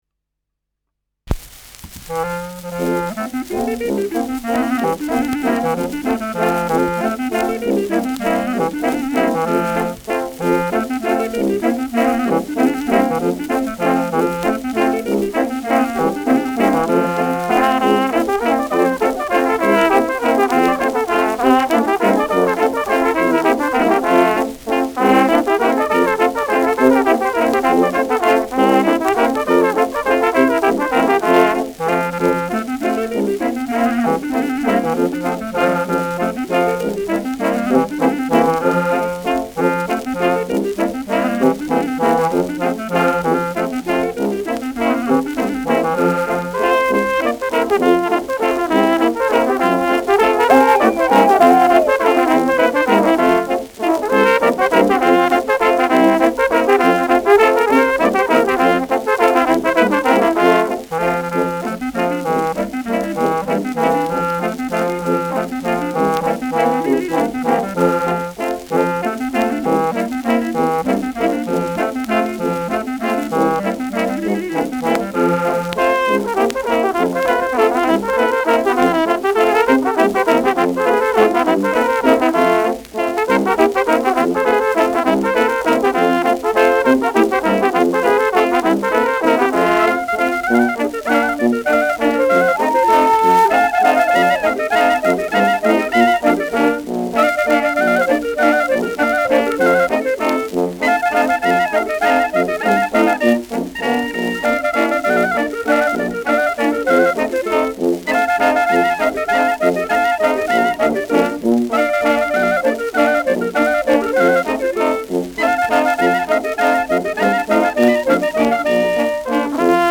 Schellackplatte
leichtes Knistern
Fränkische Bauernkapelle (Interpretation)